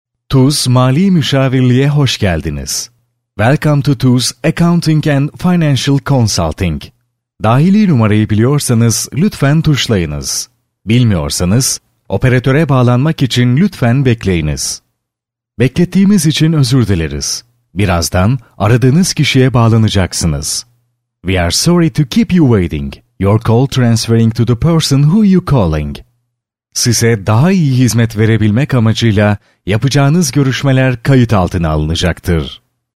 Masculino
From medium friendly read to articulate and energetic hard-sell.